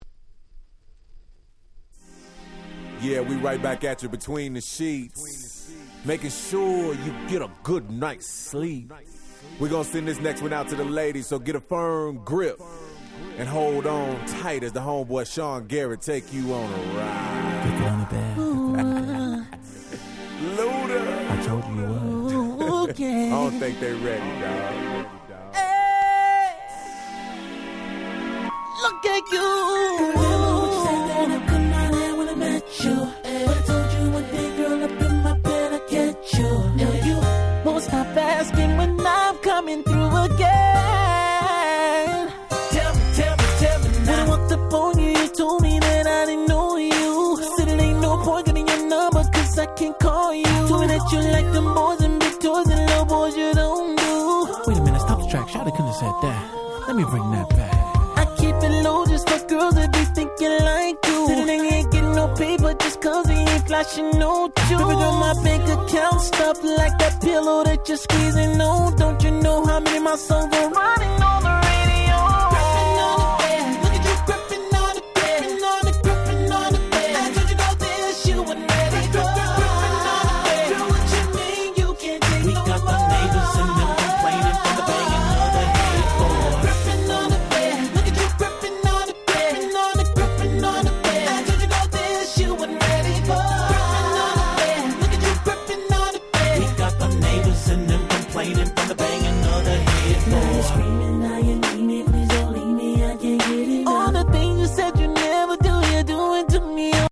【Media】Vinyl 12'' Single (Promo)
08' Super Nice R&B !!
当時リアルタイムでこの辺の新譜を追っかけていらっしゃった方にはお馴染みの超名曲Slow R&B !!
こんなにSexyなSlow、なかなかございません。